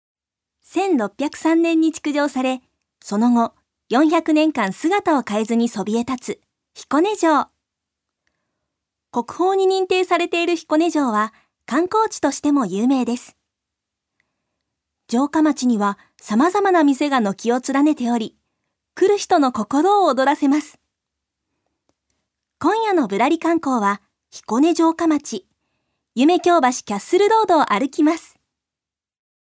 女性タレント
音声サンプル
ナレーション１